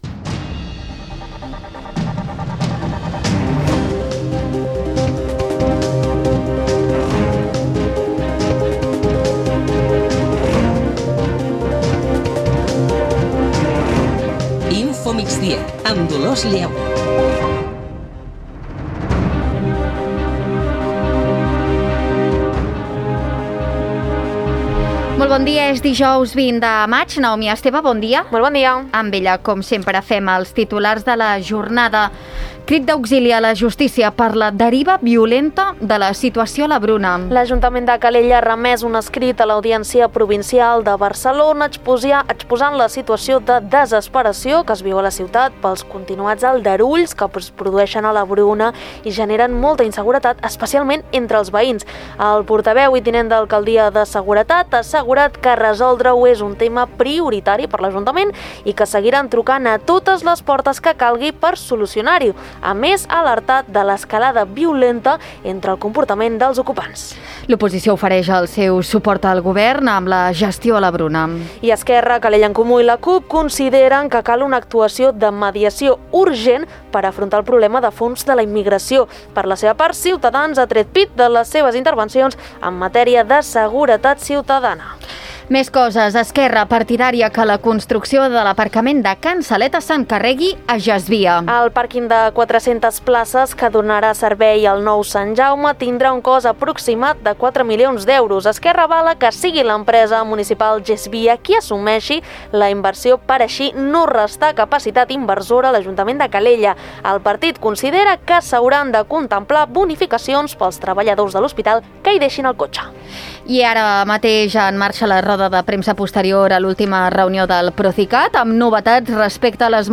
Notícies d'actualitat local i comarcal.